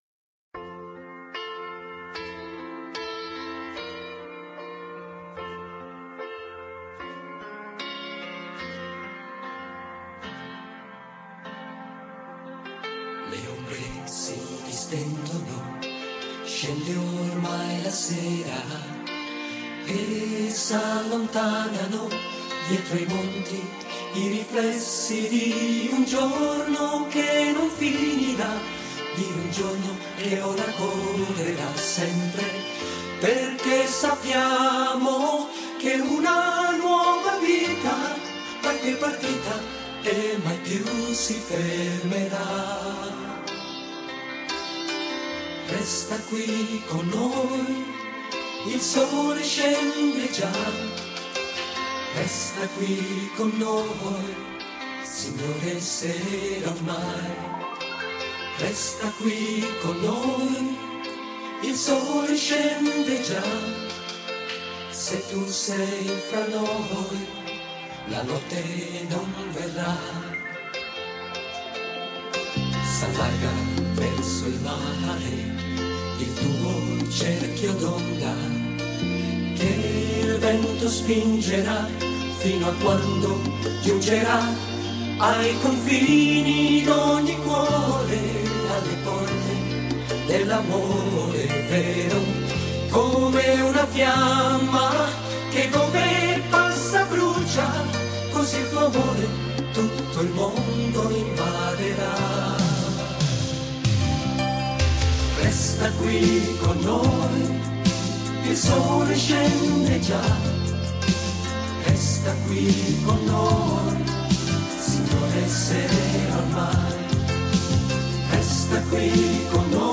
Original italien (basse qualité)